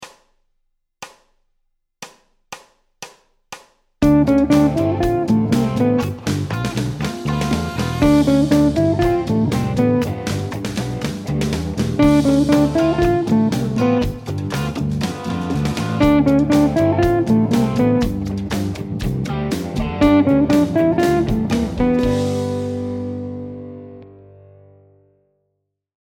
Motif avec pivot sur la Tierce, démarrant par une fioriture chromatique de la Fondamentale et finissant sur une montée chromatique de la Quinte à la Sixte.
Dans un ‘ii . V7’ le deuxième groupe de croches peut être vu comme une approche chromatique vers la Tierce de la Dominante.
Phrase 08 – Cadence ii . V7 en Majeur
Phrase de gamme avec saut de note et chromatismes (empruntée à J. Coltrane)
Phrase-08-Cadence-ii-V7.mp3